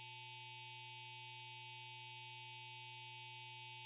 cockpit.wav